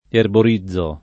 erborizzare v.; erborizzo [ erbor &zz o ]